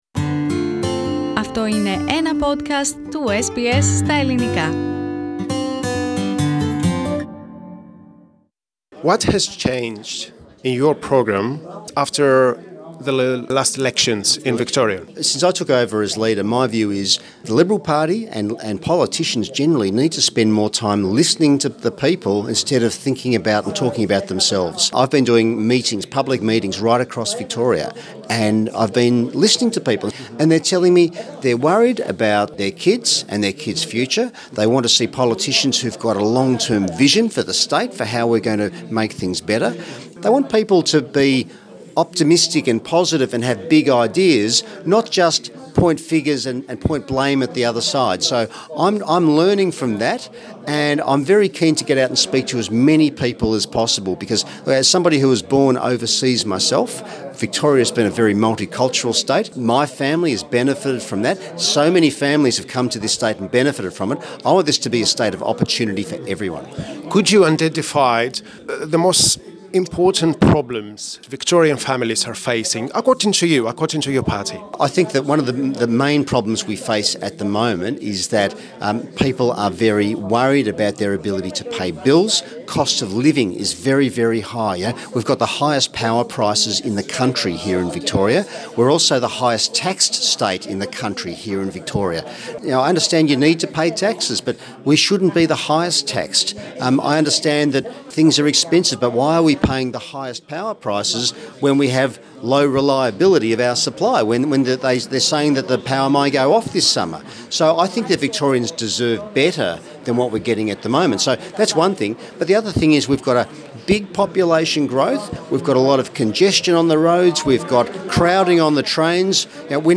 Εκεί είχε συνάντηση με φορείς της ομογένειας και μίλησε στο Ελληνικό Πρόγραμμα της Ραδιοφωνίας SBS.
Victorian Liberal leader Michael O'Brien.